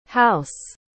Pronúncia de house em inglês:
house.mp3